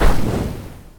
spell-impact-1.ogg